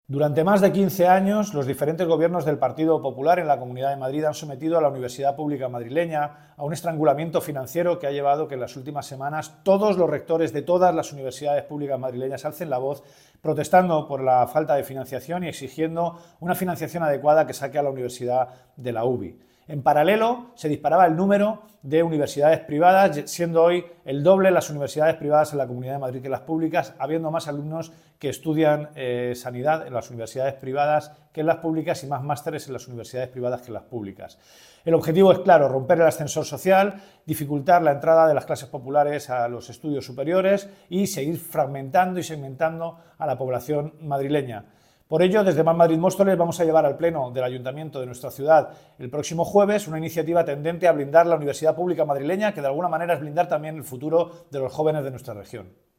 declaraciones-emilio-delgado-universidades.mp3